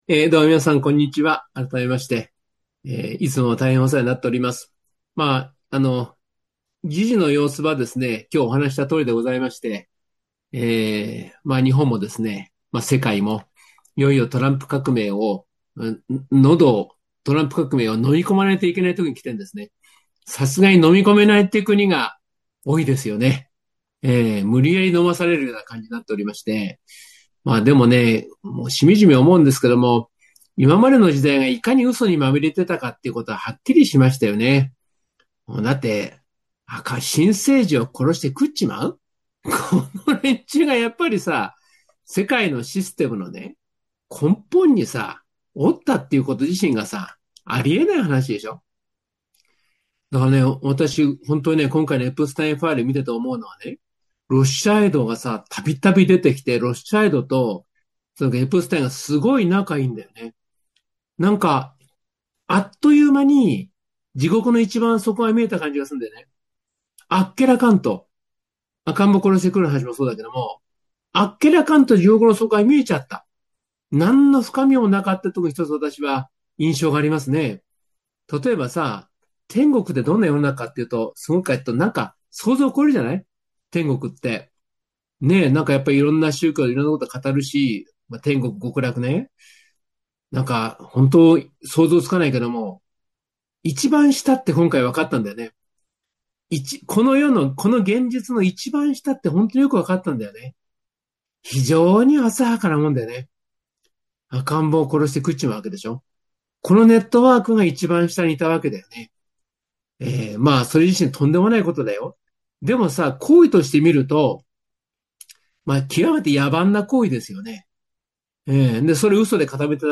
第113回NSP時局ならびに日本再生戦略講演会